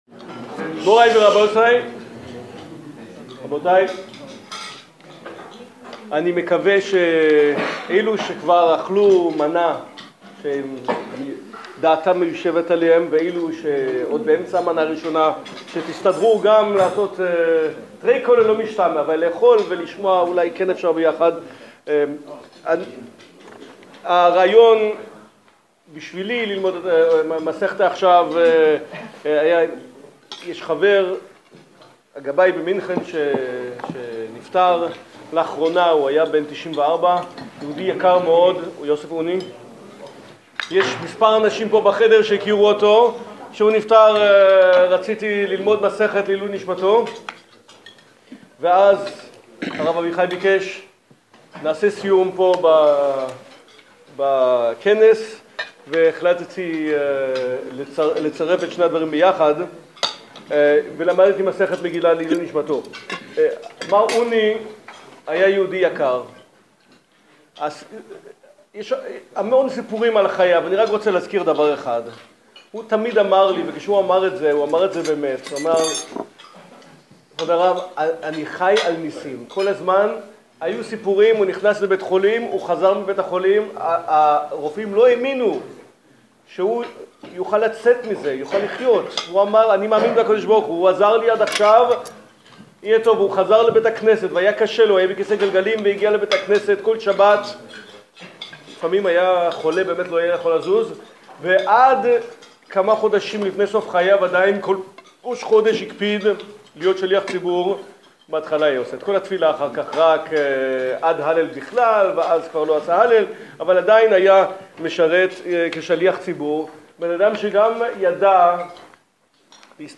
סיום המסכת דלהלן התרחש כמה שבועות אחרי זה, באסיפת החצי־שנתית של ועידת הרבנים האורתודוקסים דמדינת אשכנז.